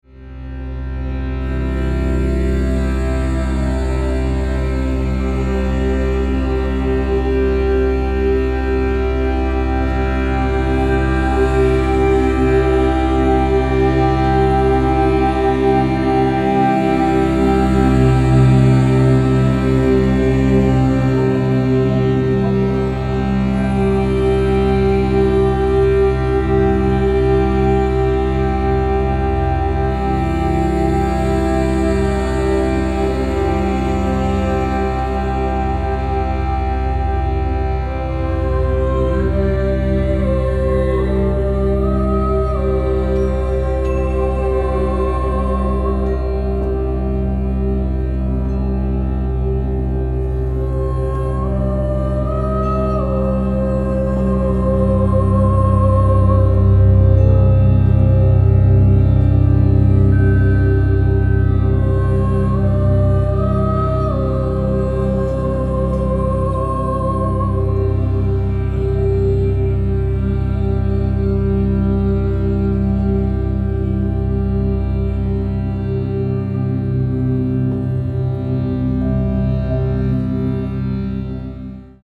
Mallets